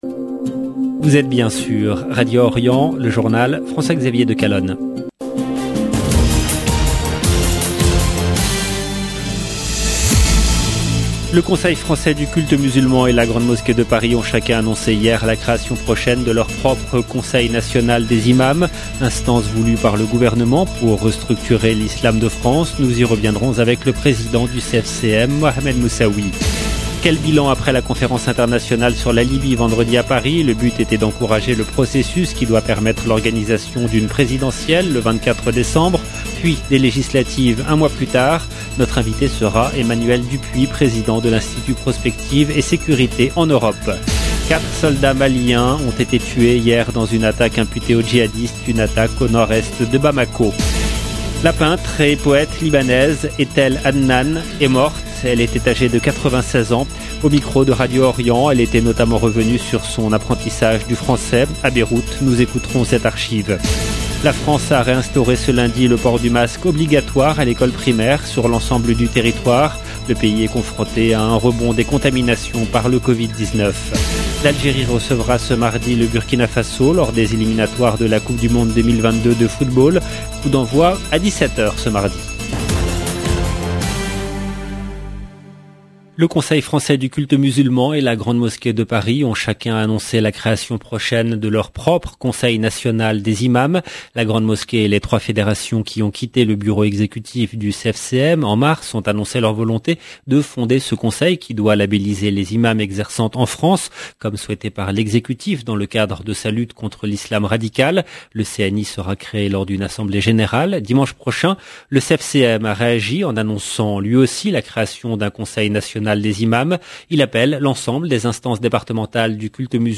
Journal présenté par
Au micro de Radio Orient, elle était notamment revenue sur son apprentissage du Français à Beyrouth.